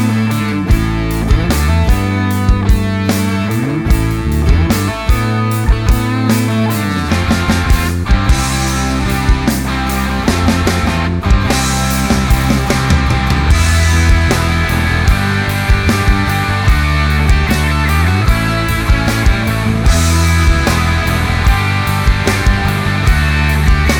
Full Length Version Rock 5:47 Buy £1.50